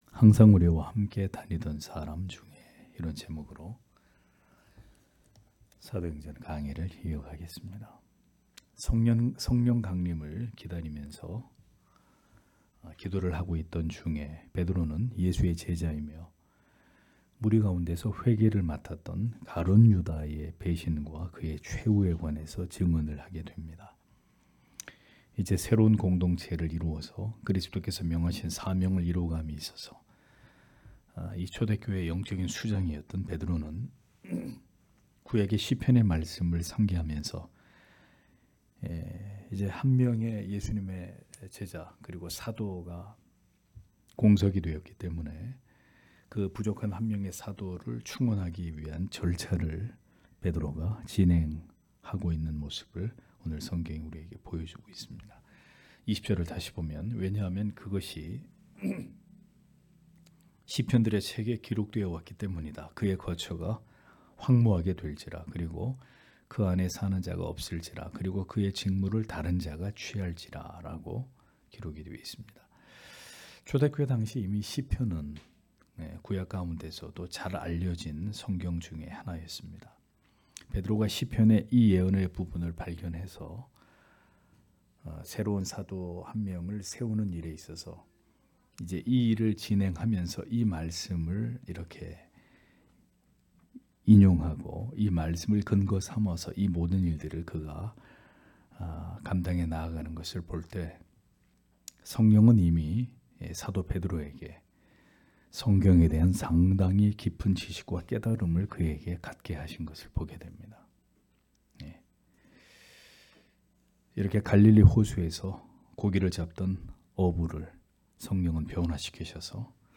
금요기도회 - [사도행전 강해 07] 항상 우리와 함께 다니던 사람 중에 (행 1장 20- 22절)